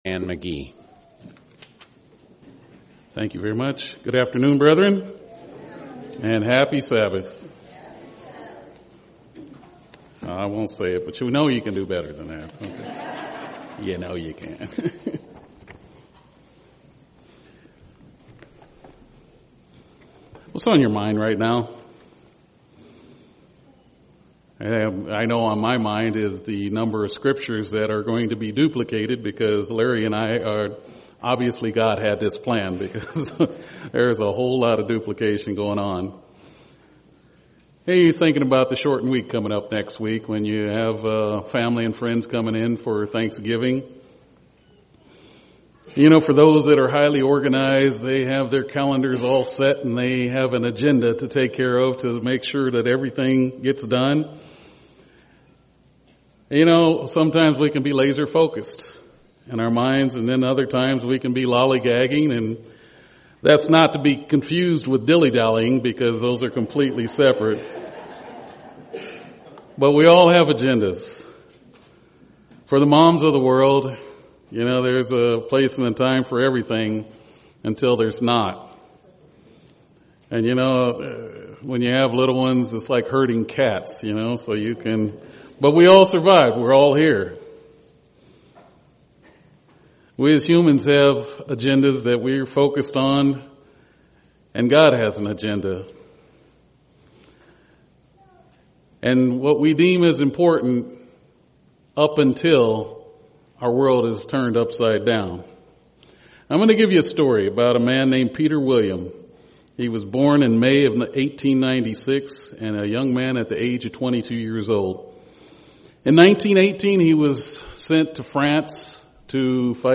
This is a Beverage only Sabbath UCG Sermon Studying the bible?